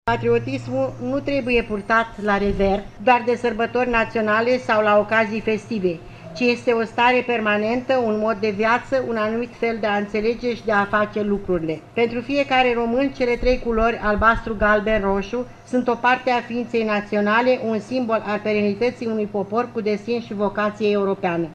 La Iaşi, în Piaţa Palatului, s-a desfăşurat un ceremonial militar şi altul religios.
Subprefectul Valentina Iosub a dat citire mesajului Ministrului de Interne, Carmen Dan: